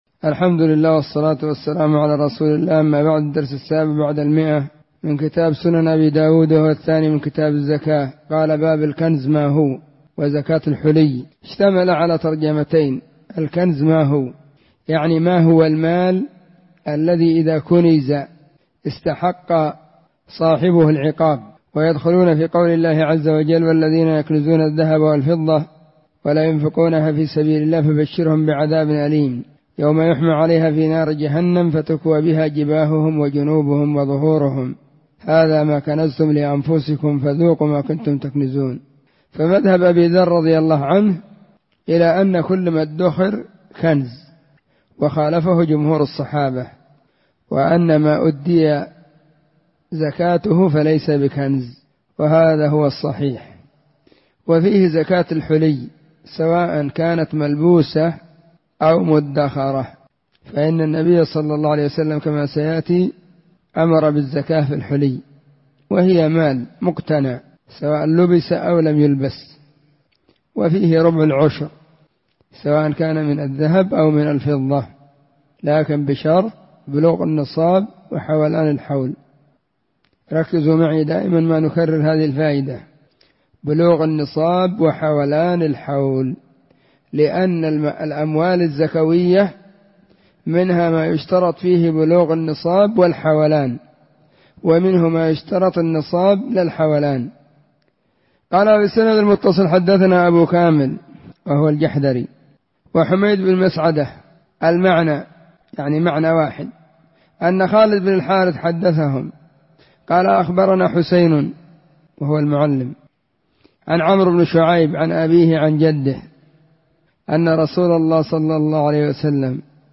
🕐 [بعد صلاة العصر في كل يوم الجمعة والسبت]
📢 مسجد الصحابة بالغيضة, المهرة، اليمن حرسها الله.